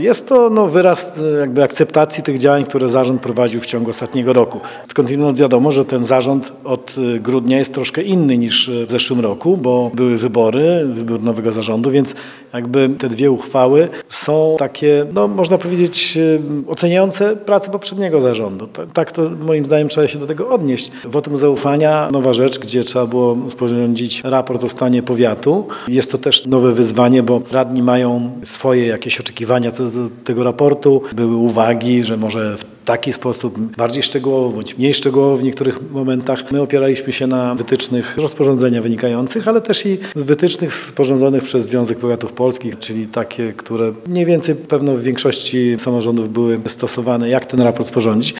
-Cieszę się, że zarząd powiatu uzyskał poparcie rady – mówi Marek Chojnowski, starosta powiatu ełckiego.